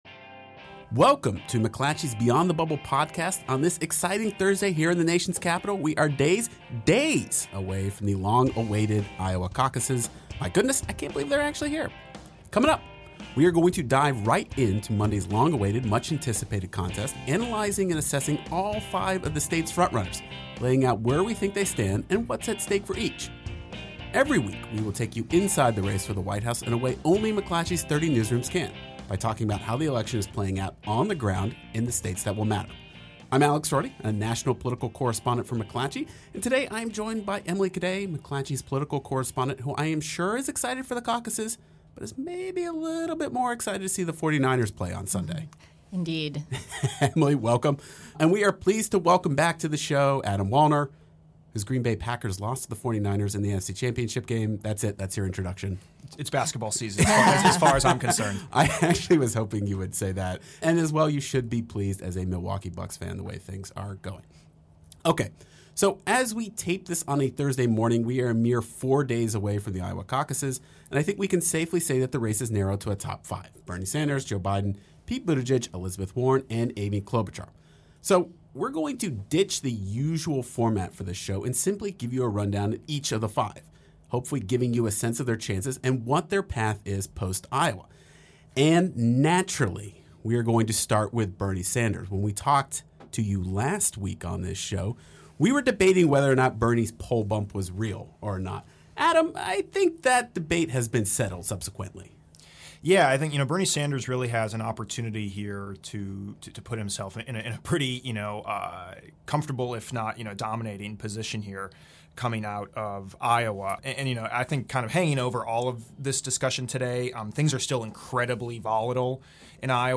national political correspondents
national politics editor